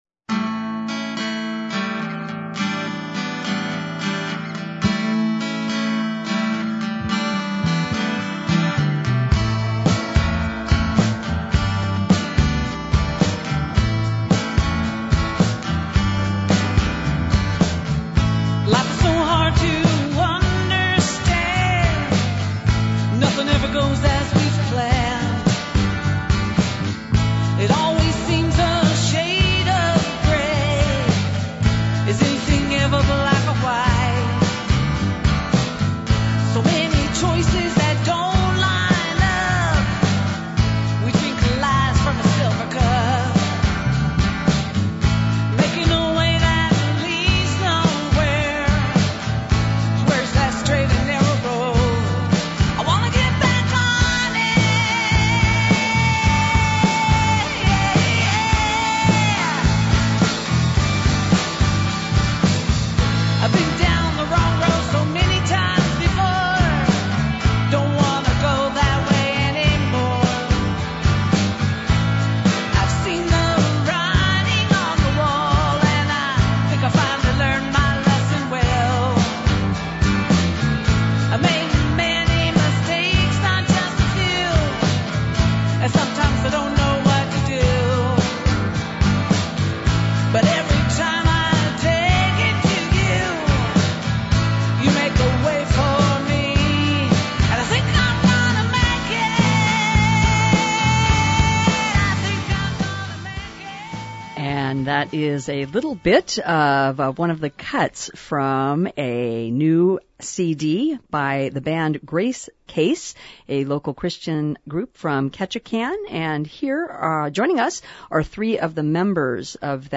Several members of the band “Gracecase” speak about how the band started, their music, and why they decided to produce a CD.